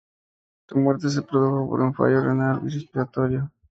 Pronounced as (IPA) /ˈfaʝo/